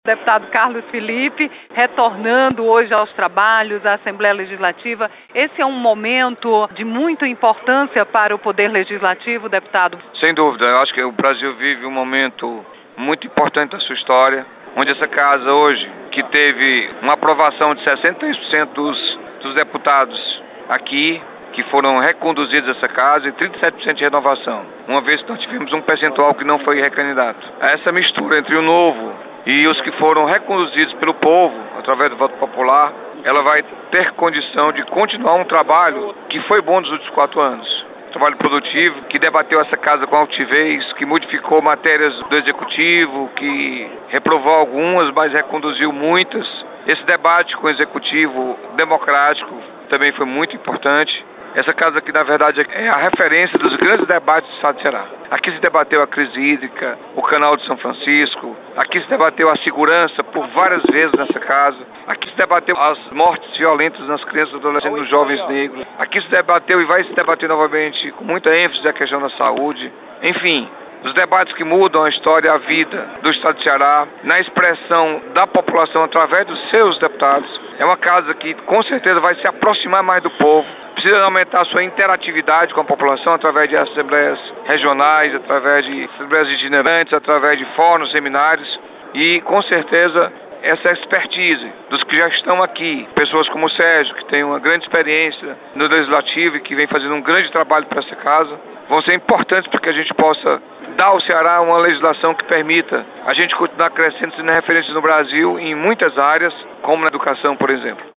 Deputado Dr. Carlos Felipe ressalta que nova composição da Assembleia Legislativa precisa entender o que pensa a sociedade brasileira a partir do recado repassado nas urnas.